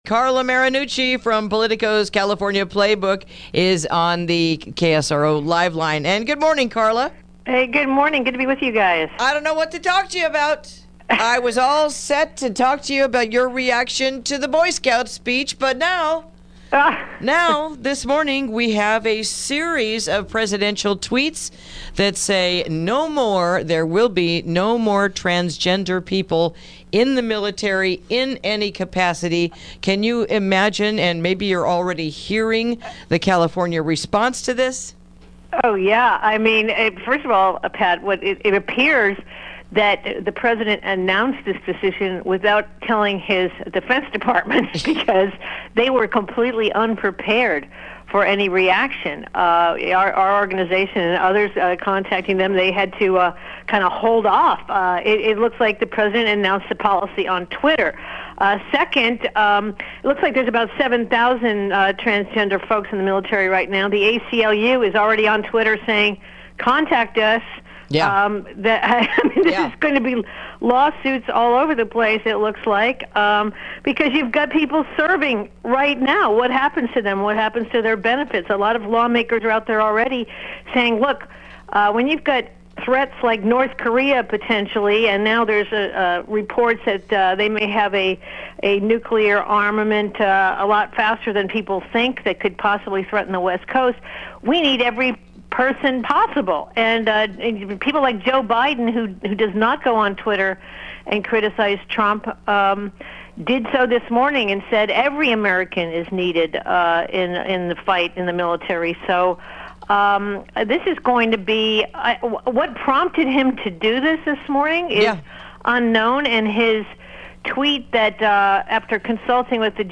Interview: A Look Around the Golden State | KSRO 103.5FM 96.9FM & 1350AM